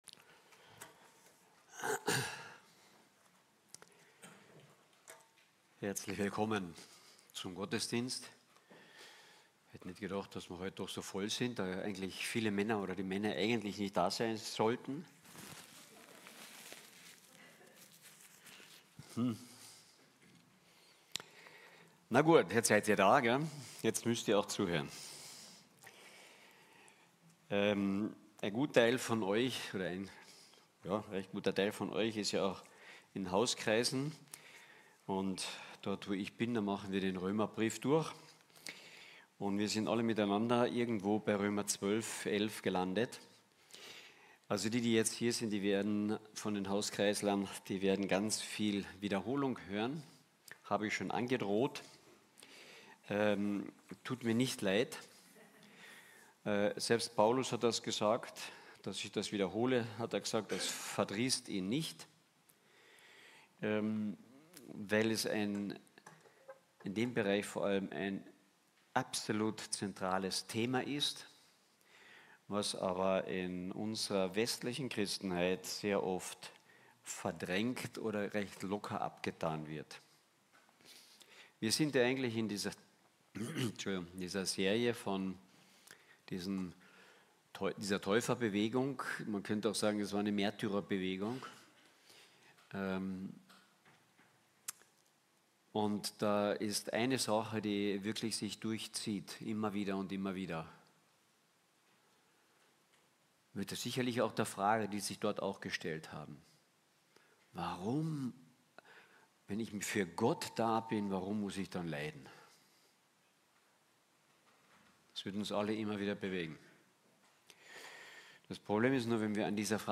Eine predigt aus der serie "Täufer."